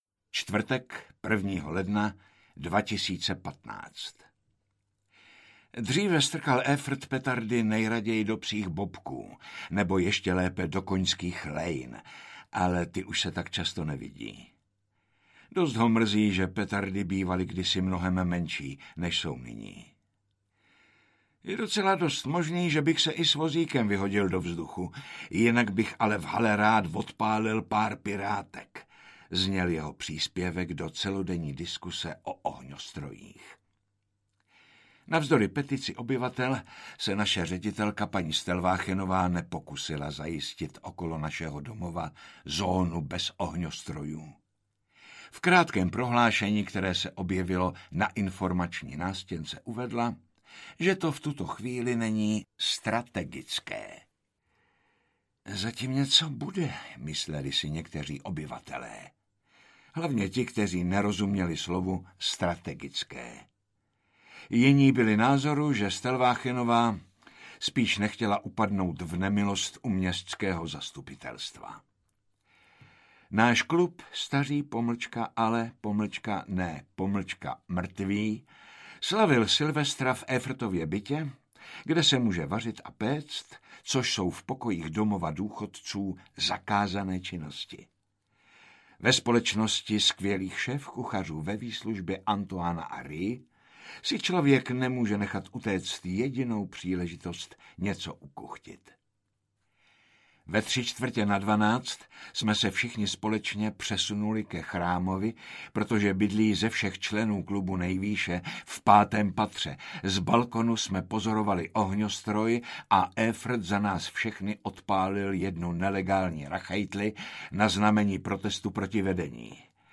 Nový tajný deník Hendrika Groena, 85 let audiokniha
Ukázka z knihy
• InterpretJaromír Meduna